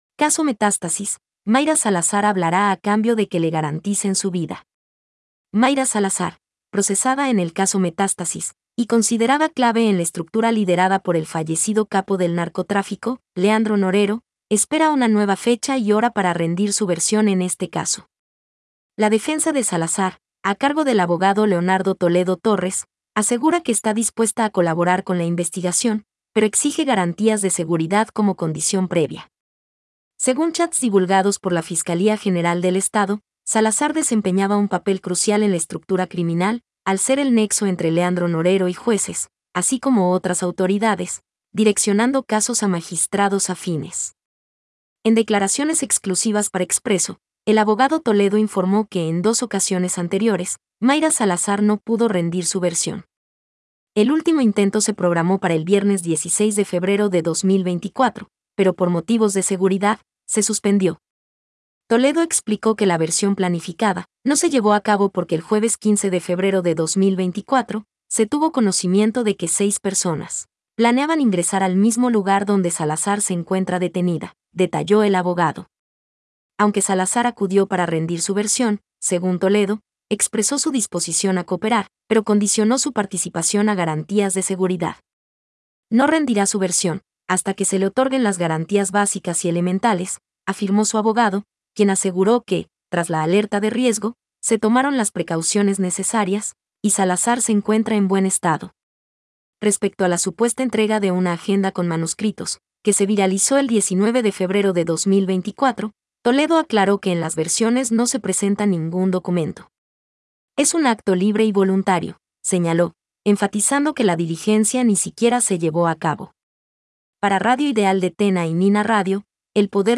Noticia hablada